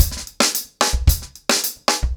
TimeToRun-110BPM.9.wav